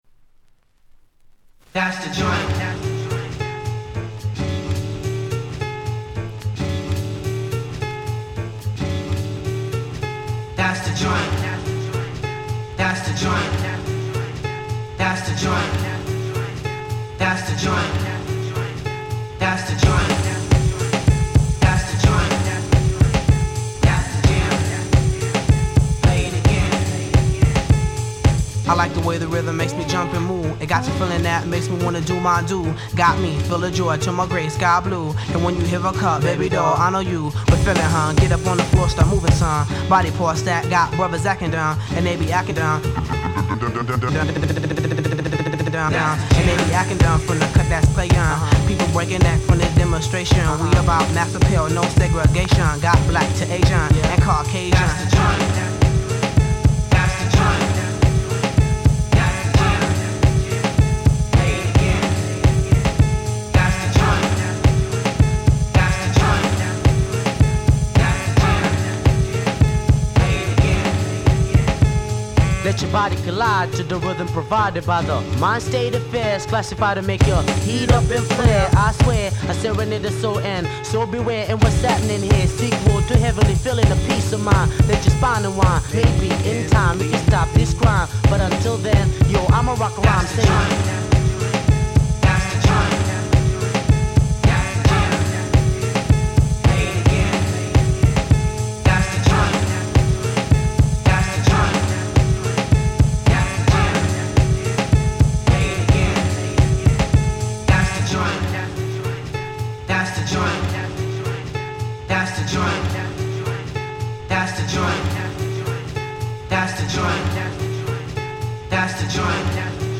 98' Smash Hip Hop !!
US Promo Only Remix !!